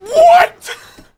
Play Jeers 8 - SoundBoardGuy
scout_negativevocalization03.mp3